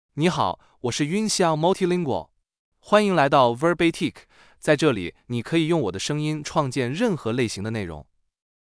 Yunxiao MultilingualMale Chinese AI voice
Yunxiao Multilingual is a male AI voice for Chinese (Mandarin, Simplified).
Voice sample
Listen to Yunxiao Multilingual's male Chinese voice.
Male